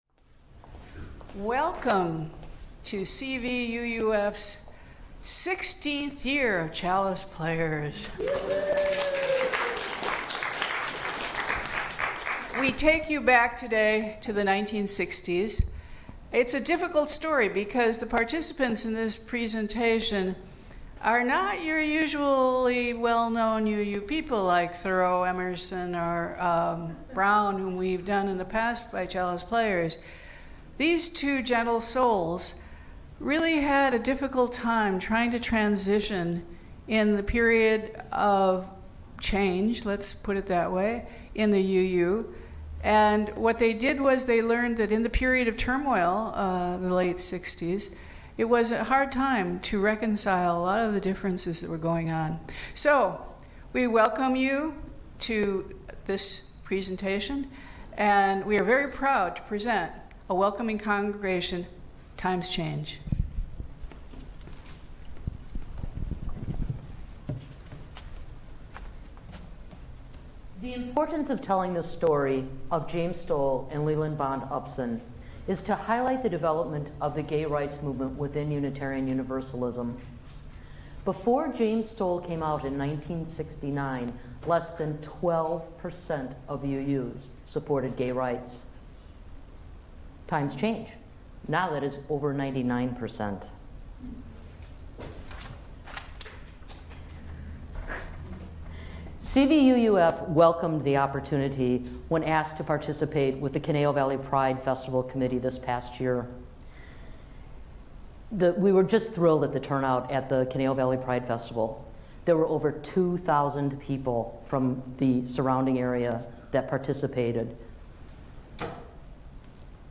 The iconic music of the 1960’s will take us back to that revolutionary time.